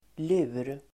Uttal: [lu:r]